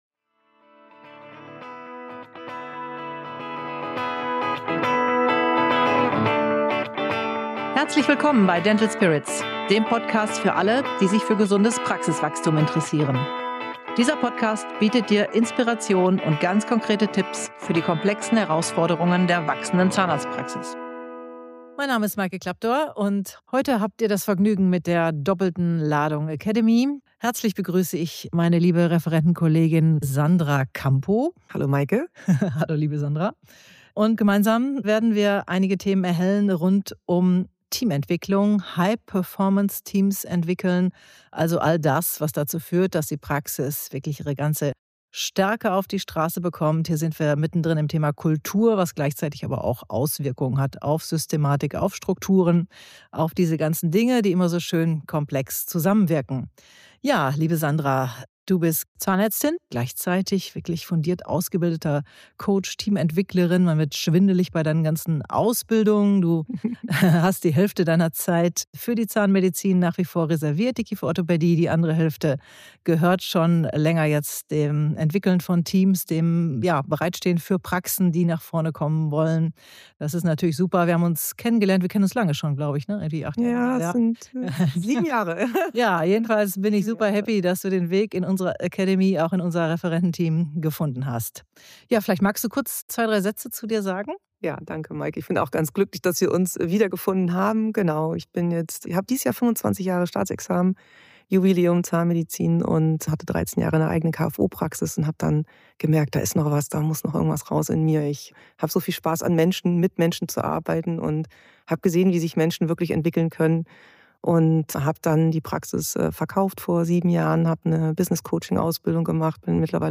ein lebhaftes Gespräch.